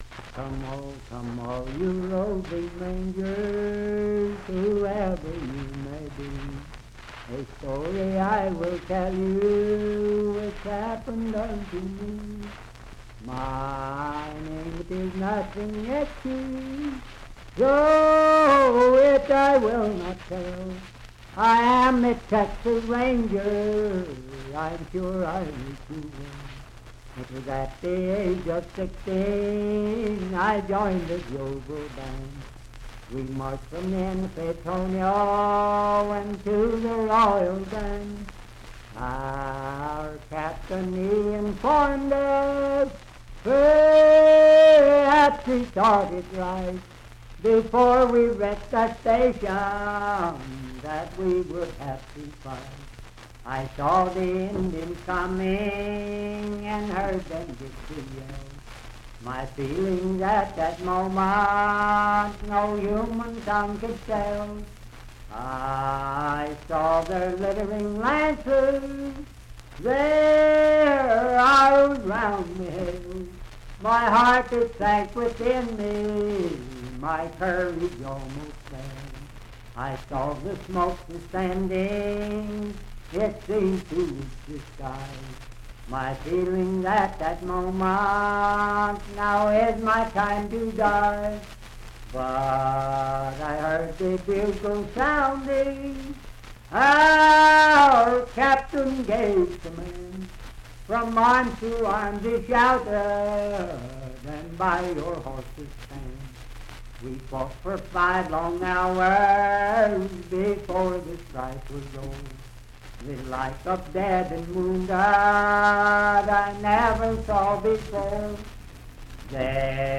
Unaccompanied vocal music
Voice (sung)
Parkersburg (W. Va.), Wood County (W. Va.)